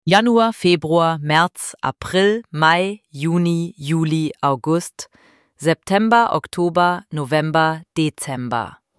IZGOVOR – MJESECI:
ElevenLabs_Text_to_Speech_audio-50.mp3